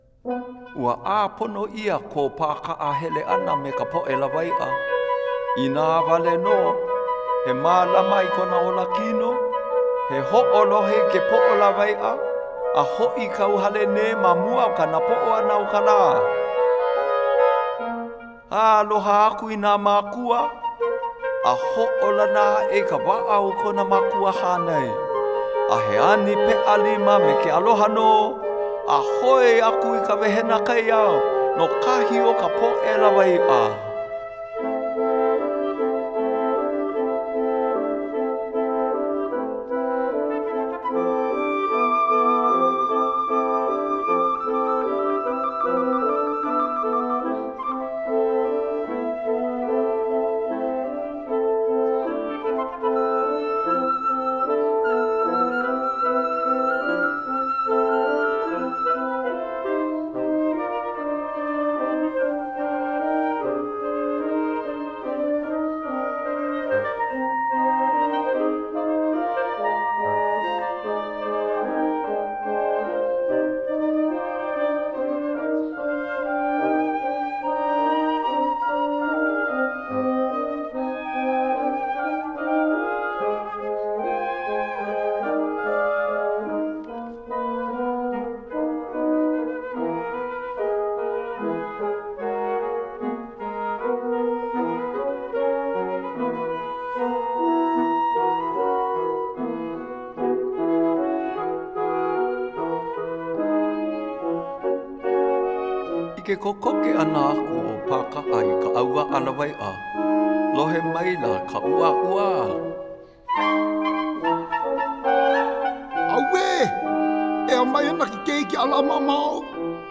wind quintet and narrator